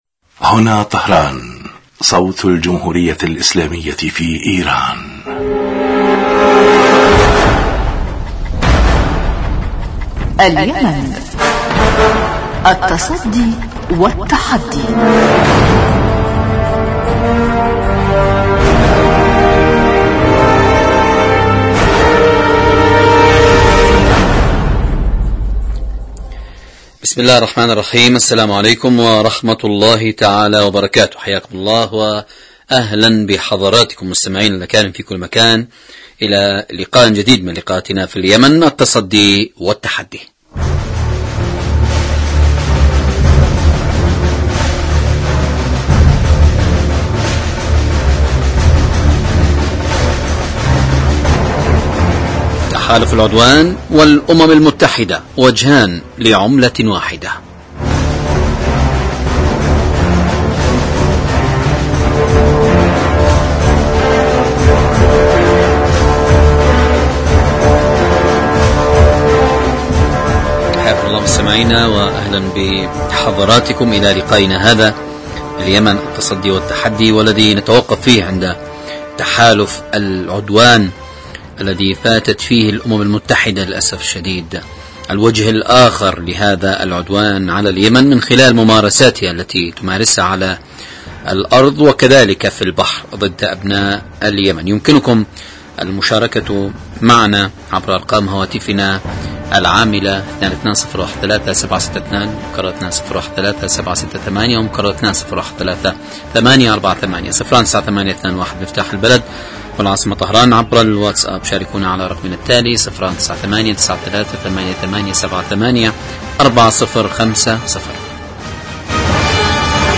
برنامج سياسي حواري يأتيكم مساء كل يوم من إذاعة طهران صوت الجمهورية الإسلامية في ايران
البرنامج يتناول بالدراسة والتحليل آخر مستجدات العدوان السعودي الأمريكي على الشعب اليمني بحضور محللين و باحثين في الاستوديو.